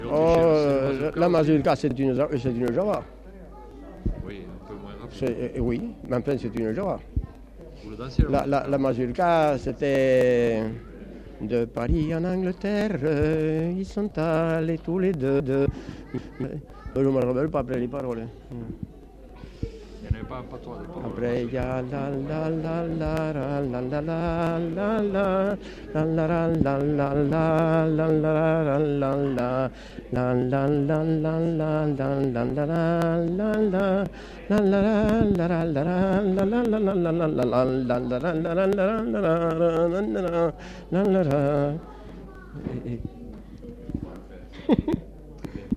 Mazurka (fredonné) Centre culturel.